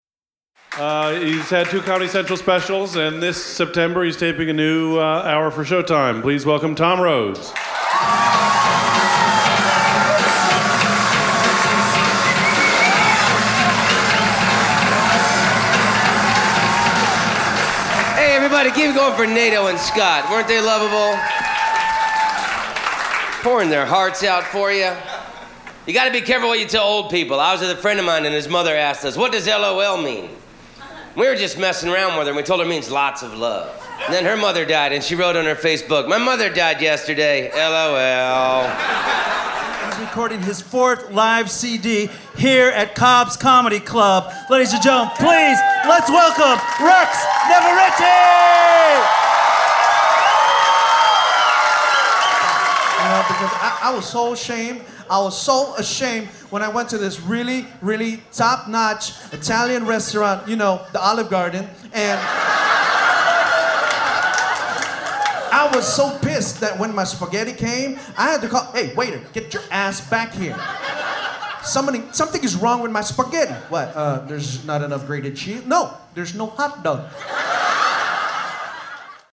Travsonic mobile recording comedy sample